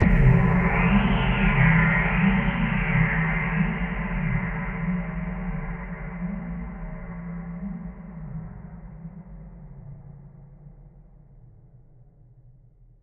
Index of /musicradar/impact-samples/Processed Hits
Processed Hits 02.wav